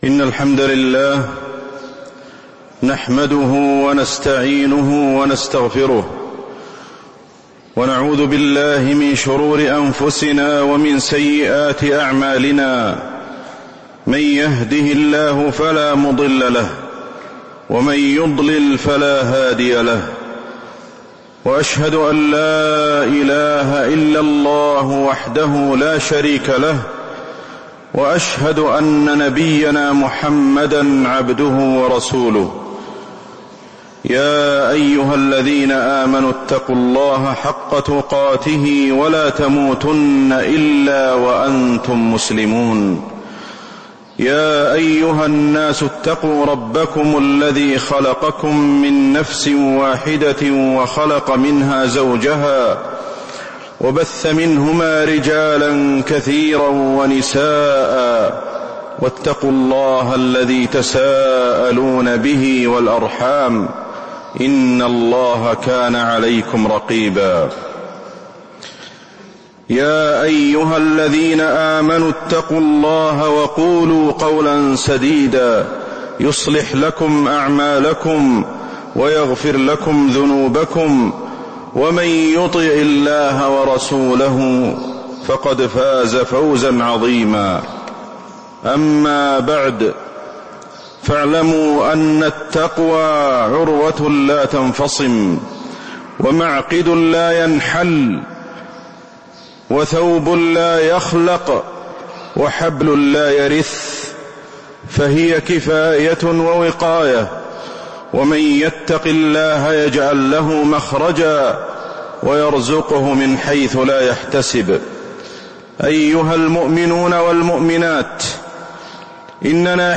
خطبة تأملات في بديع صنع الخالق وفيها: التفكر في آيات الله في الكون وما تحققه من حب الله في قلوب العباد
تاريخ النشر ٥ رجب ١٤٤٤ المكان: المسجد النبوي الشيخ: فضيلة الشيخ أحمد الحذيفي فضيلة الشيخ أحمد الحذيفي تأملات في بديع صنع الخالق